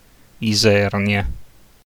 Isernia (Italian pronunciation: [iˈzɛrnja]